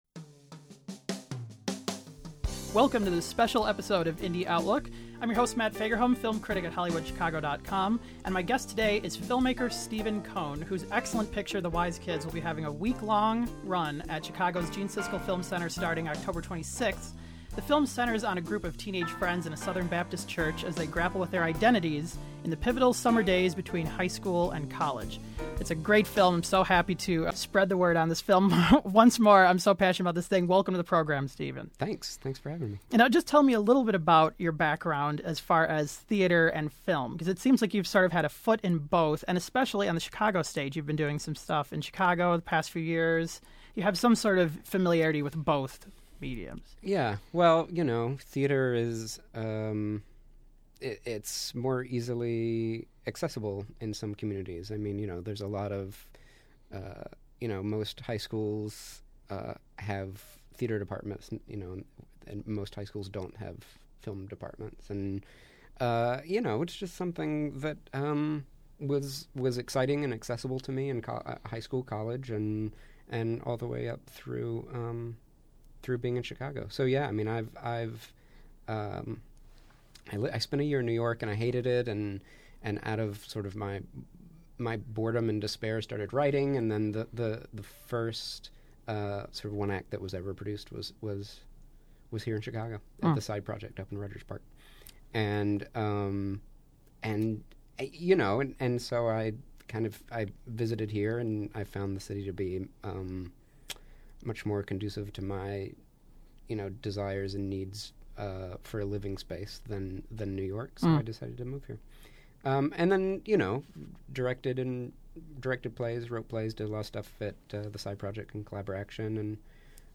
The program was produced at Columbia College Chicago.